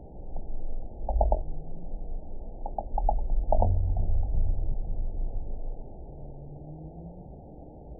event 910354 date 01/18/22 time 17:57:09 GMT (3 years, 5 months ago) score 5.89 location TSS-AB06 detected by nrw target species NRW annotations +NRW Spectrogram: Frequency (kHz) vs. Time (s) audio not available .wav